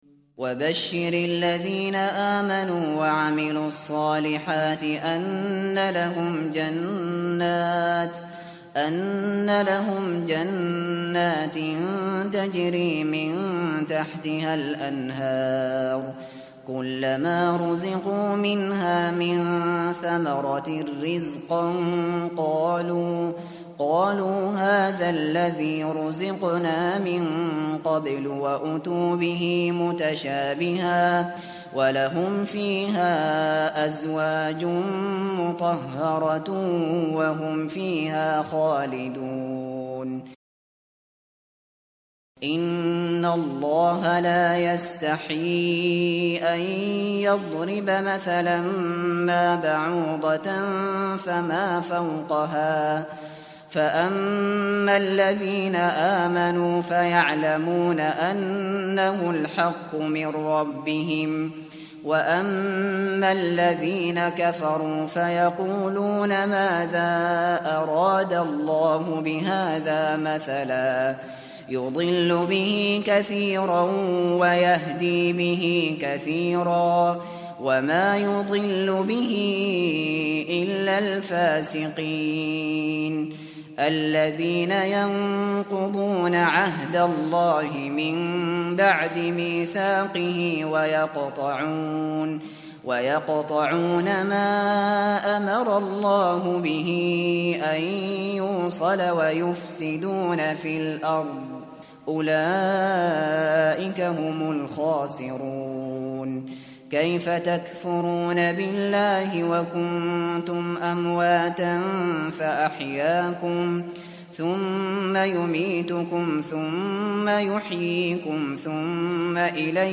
Hafız Abu Bakr al Shatri sesinden Cüz-1, Sayfa-5 dinle!
Hafız Maher Al Mueaqly sesinden Cüz-1, Sayfa-5 dinle!
Hafız Mishary AlAfasy sesinden Cüz-1, Sayfa-5 dinle!